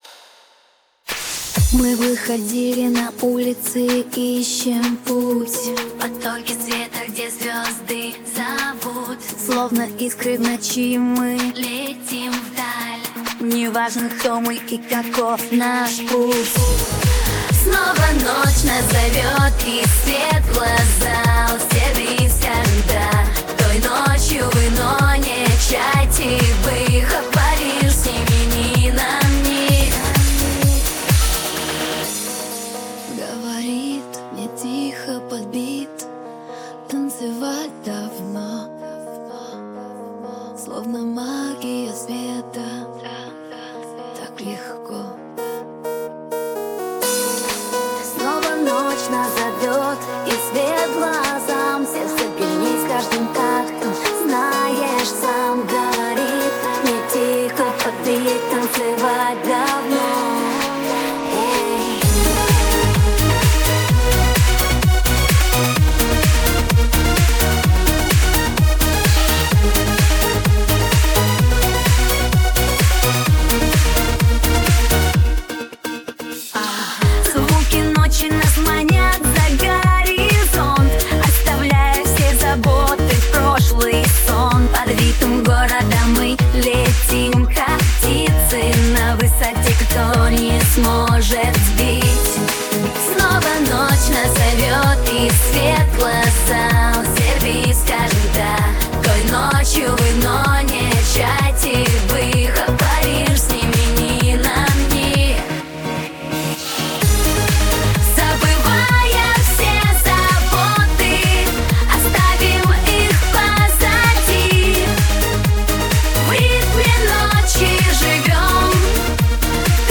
Давненько делаю эту песню (с переменным успехом аж с 2009 года), изначально хочется сделать радиоэфирную танцевальную электронную попсу.